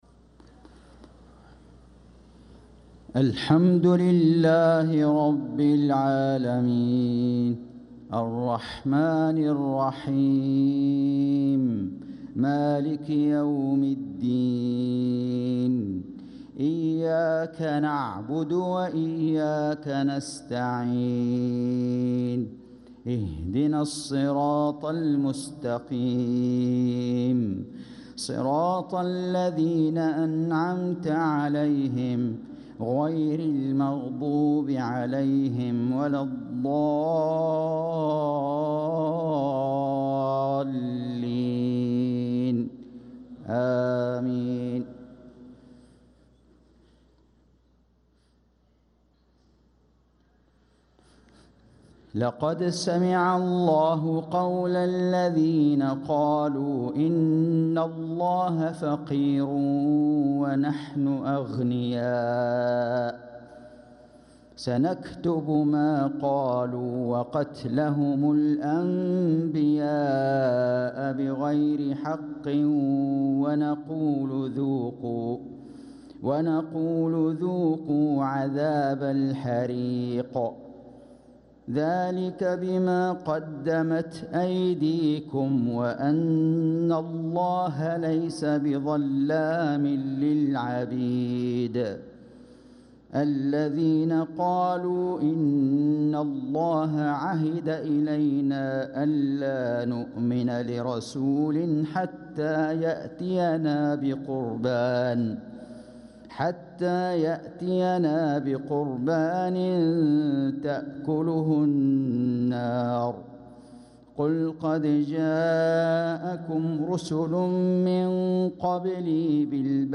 صلاة العشاء للقارئ فيصل غزاوي 3 جمادي الأول 1446 هـ
تِلَاوَات الْحَرَمَيْن .